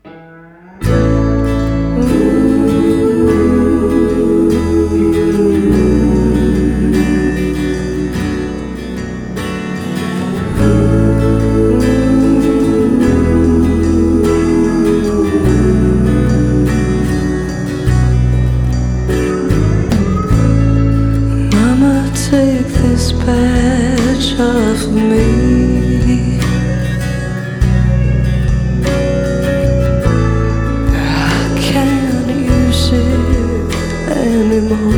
Жанр: Иностранный рок / Рок / Инди / Альтернатива / Фолк-рок